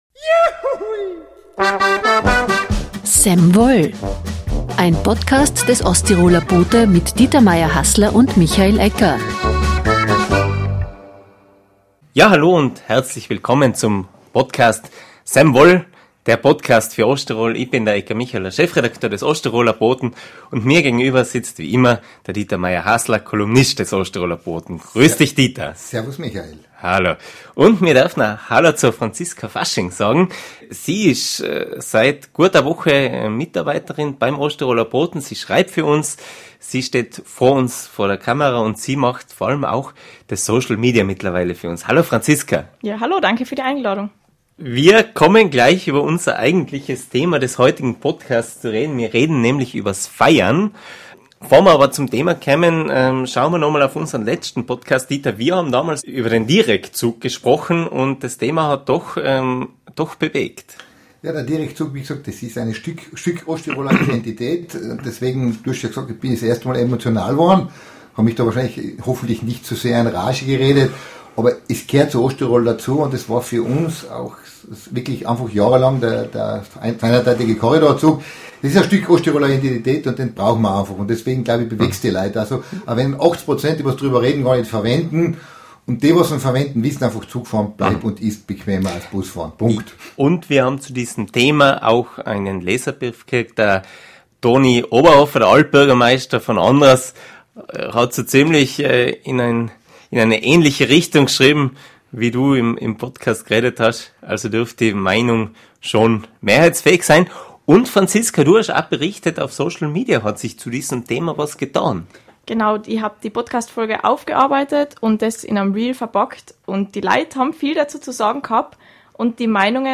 Warum zieht das Sommerfest in Lienz Massen an? Ein Gespräch über das Feiern. Und: Was unterscheidet die Generationen, wenn es ums Feiern in Osttirol geht?